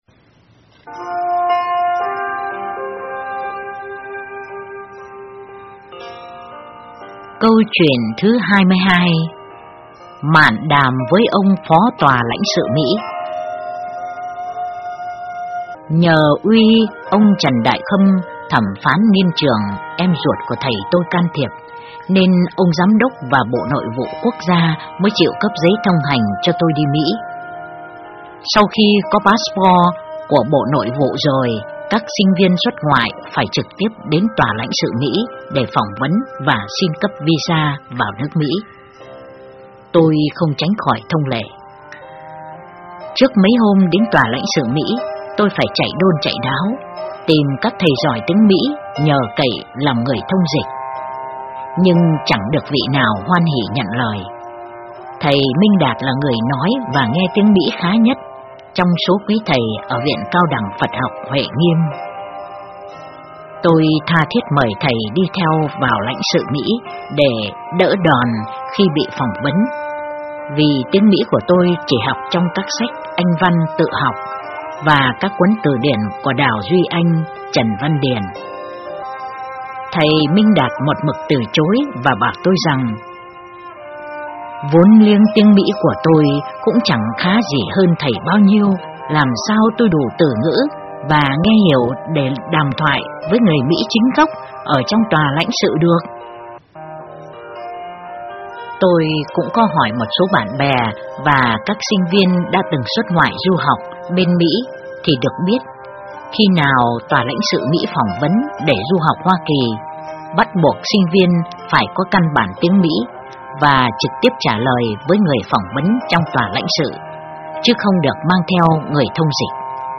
Truyện Đọc Những Mẫu Chuyện Linh Ứng Quan Thế Âm (giọng khác)